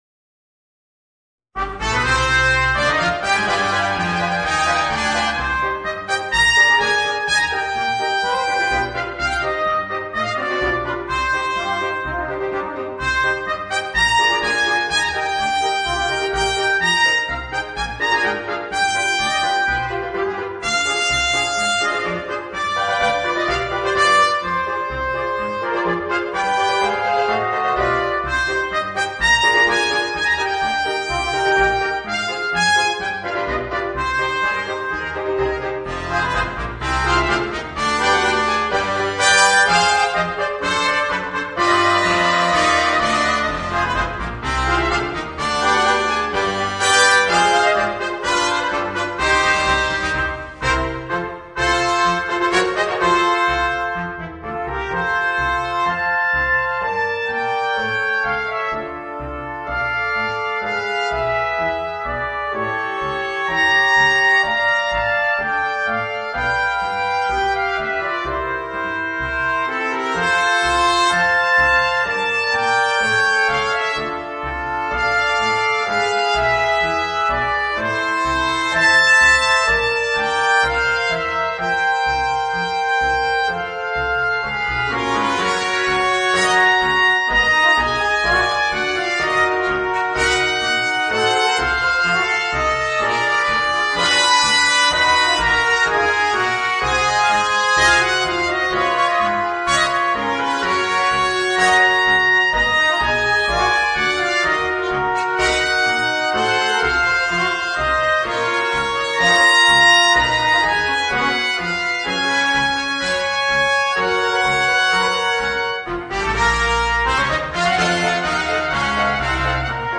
Voicing: 4 Trumpets and Piano